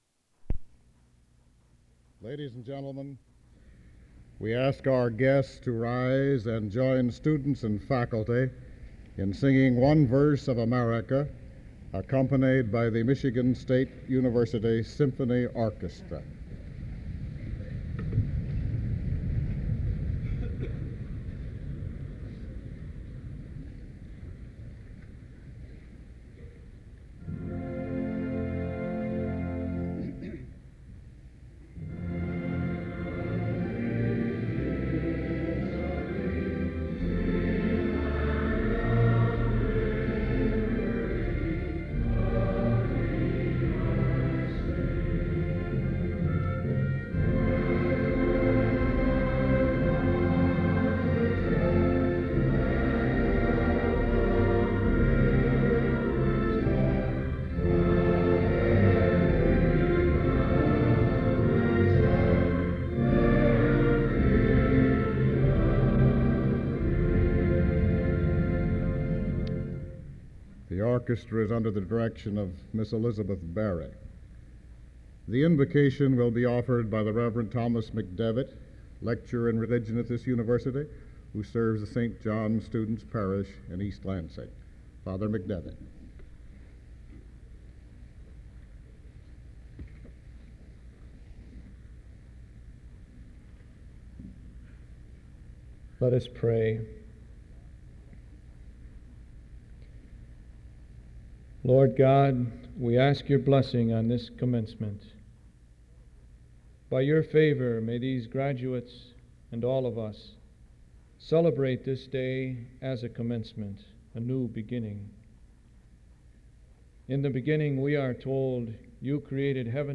Commencement Address: Winter 1967 Back Creator: WKAR Subjects: People, Students, Commencements Description: The March 1967 convocation takes place in the University Auditorium, on the campus of Michigan State University (MSU). The MSU Symphony Orchestra is presented by President John A. Hannah, and attendees of the graduation ceremony sing America .
O. Meredith Wilson, President of the University of Minnesota, gives the commencement address.